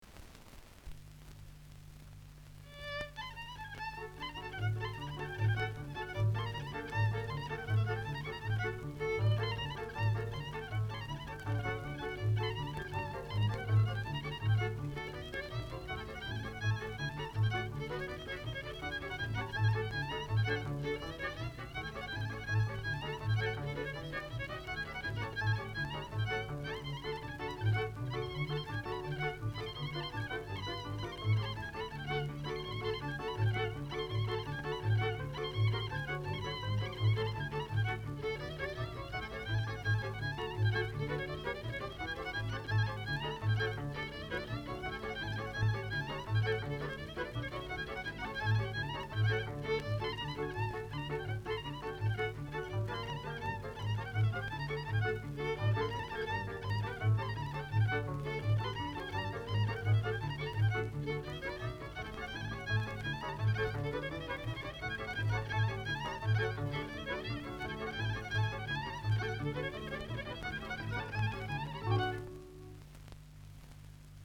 Joc popular din Teleorman (Muntenia), interpretat de [taraf neprecizat].
• folklore (culture-related concept)
• disks (object genres)